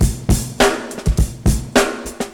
103 Bpm Tape Funk Drum Loop D# Key.wav
Free breakbeat - kick tuned to the D# note.
.WAV .MP3 .OGG 0:00 / 0:02 Type Wav Duration 0:02 Size 438,45 KB Samplerate 48000 Hz Bitdepth 16 Channels Stereo Free breakbeat - kick tuned to the D# note.
103-bpm-tape-funk-drum-loop-d-sharp-key-tZL.ogg